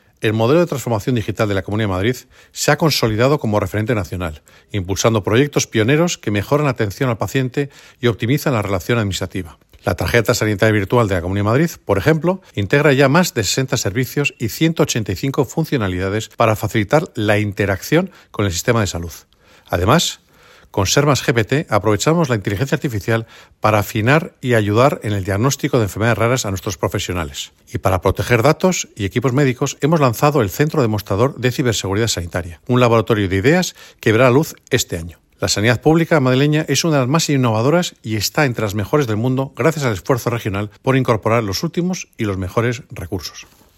Declaraciones del Consejero ]